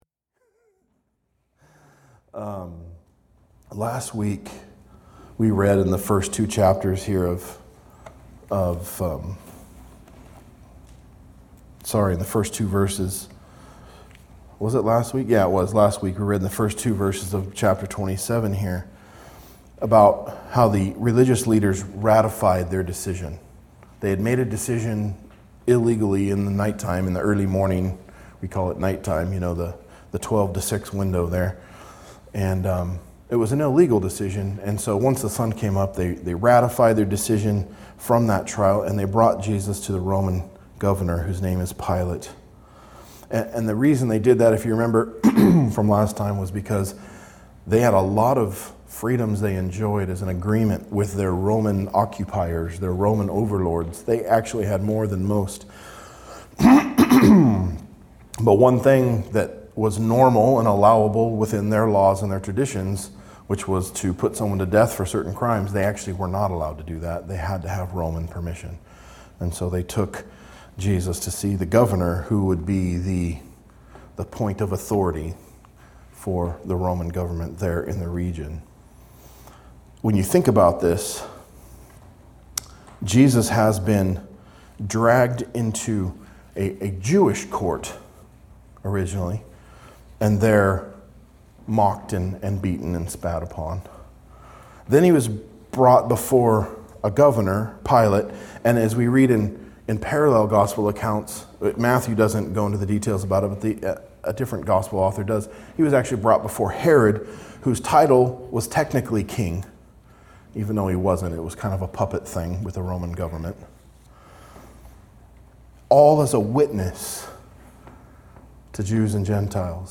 Verse by verse exposition of Matthew's Gospel